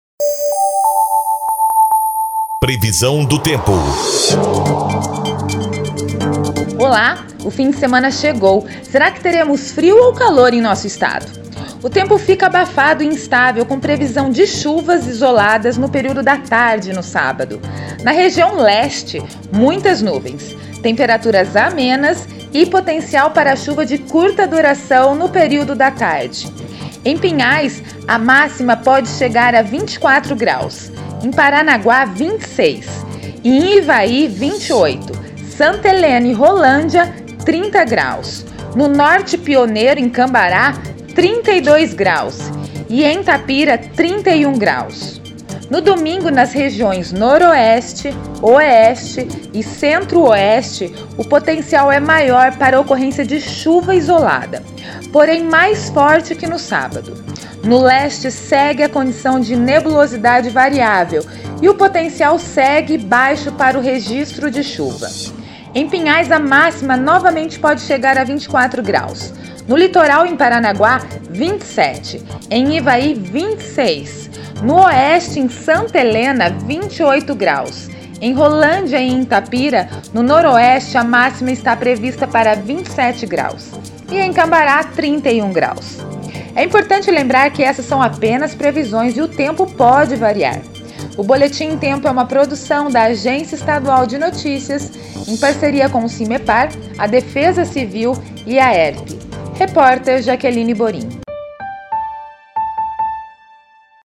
Previsão do Tempo (13/04 e 14/04)